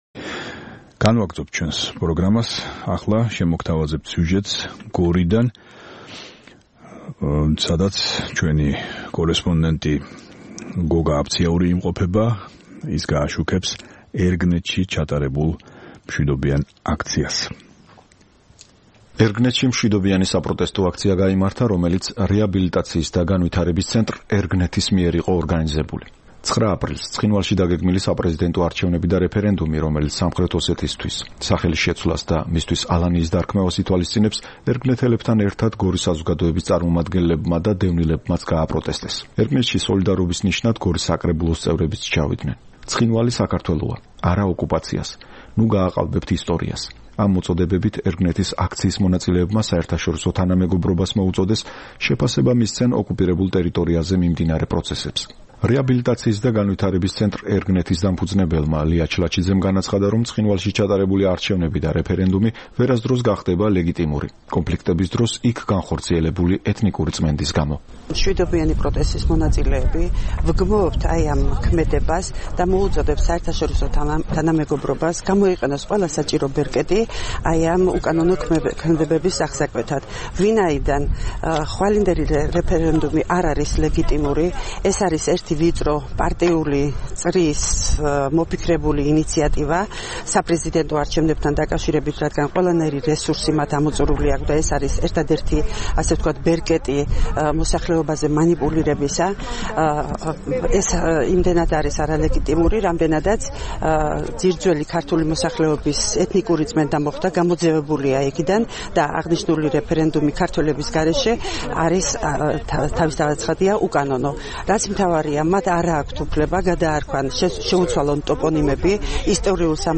აქცია ერგნეთში